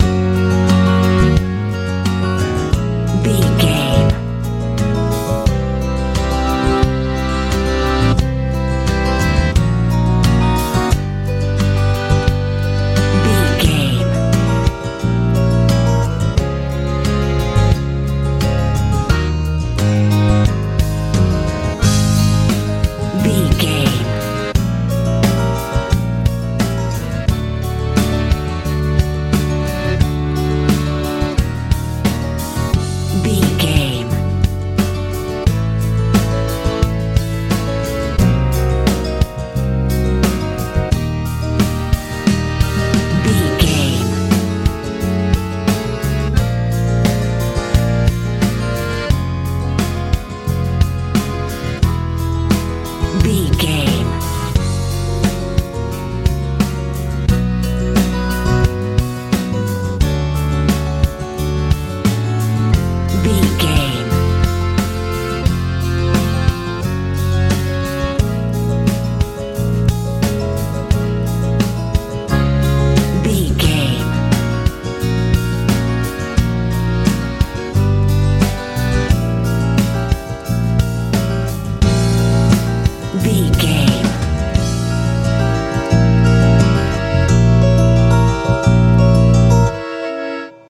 lite pop feel
Ionian/Major
F♯
joyful
accordion
acoustic guitar
bass guitar
drums